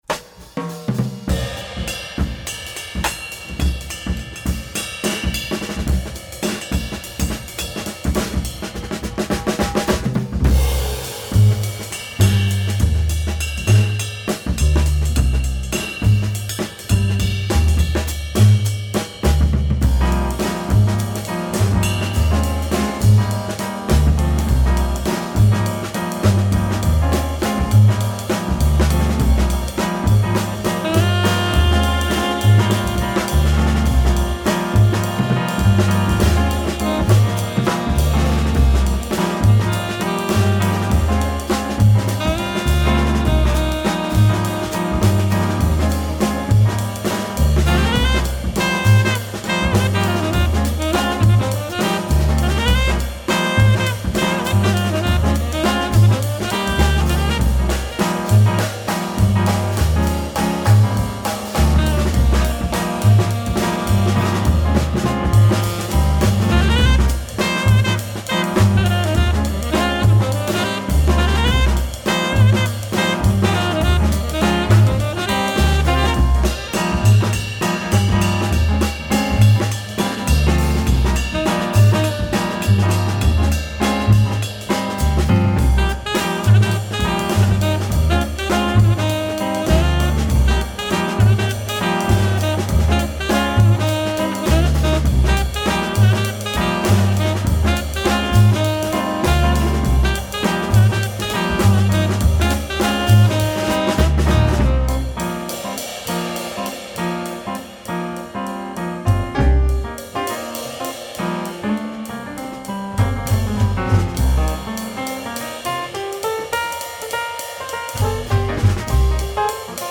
2LP)Soul / Funk / Jazz